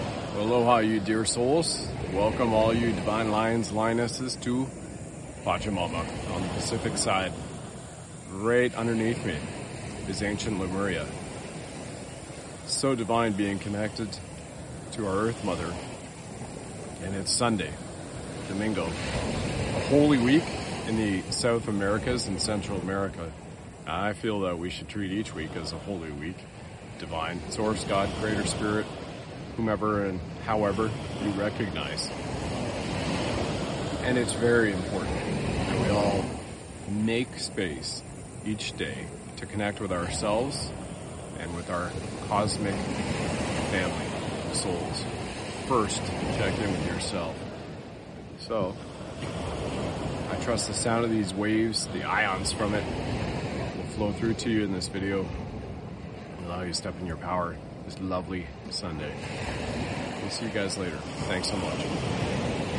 It’s crucial to take time daily to connect with ourselves and others. Enjoy the soothing sound of the waves and have a great Sunday.